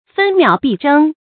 注音：ㄈㄣ ㄇㄧㄠˇ ㄅㄧˋ ㄓㄥ
分秒必爭的讀法